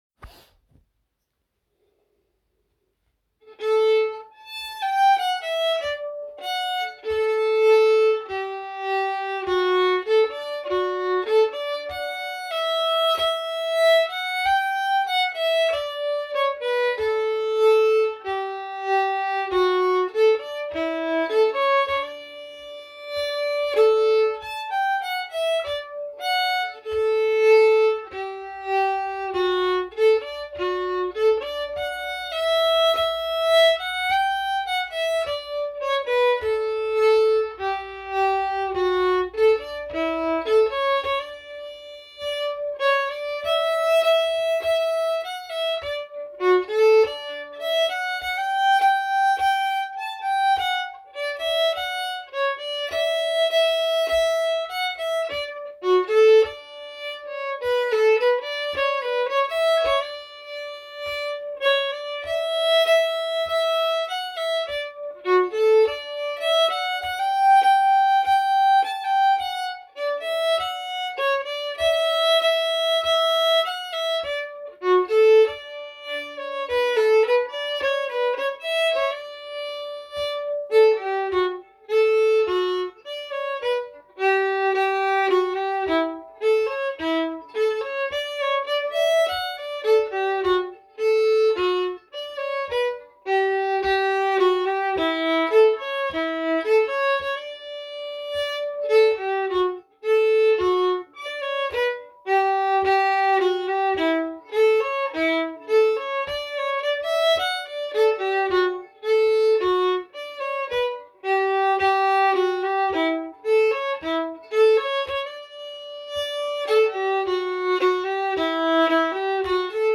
Genre Kadrilj
Spelas utan repris på B-delen till dansen Landskronakadrilj.
Kadrilj från Landskrona (långsam).mp3